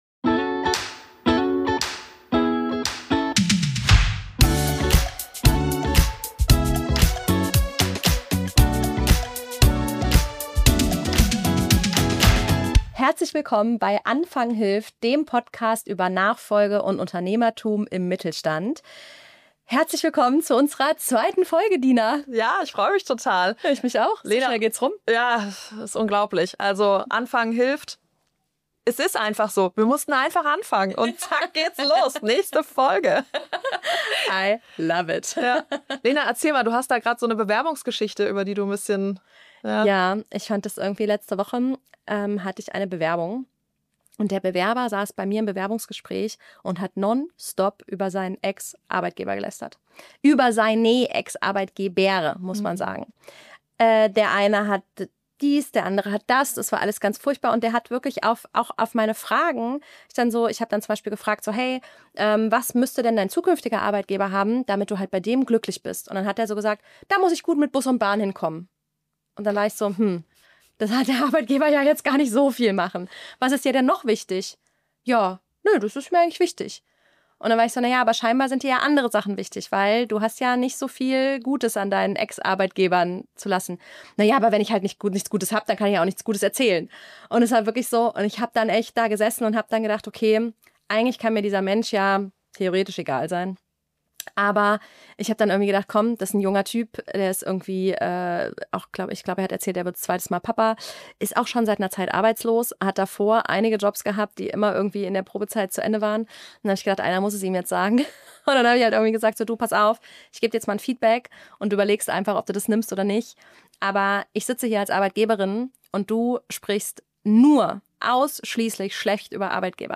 Ein ehrliches Gespräch über Selbstreflexion, Durchhaltevermögen und das Standing, das Unternehmerinnen für sich und ihre Teams entwickeln müssen.